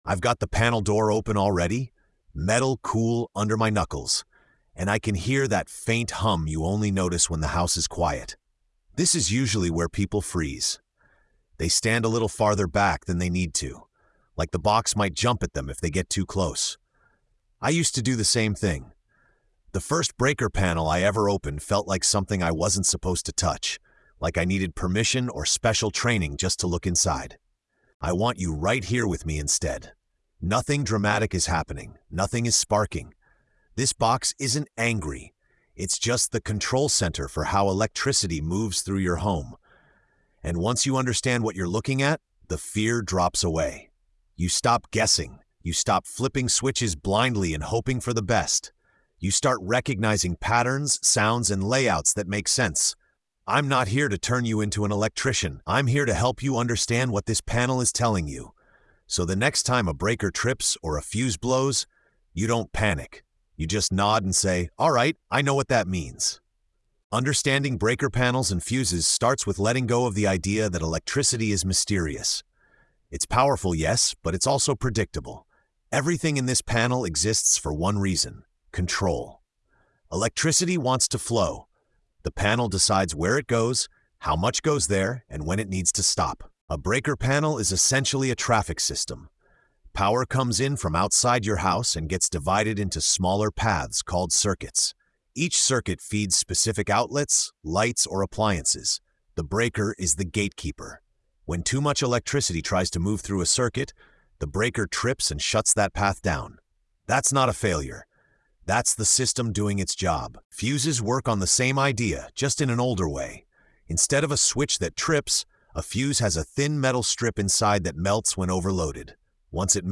Through calm, hands-on explanation, the episode demystifies breakers and fuses—not as dangerous mysteries, but as logical systems designed to protect the house and the people inside it.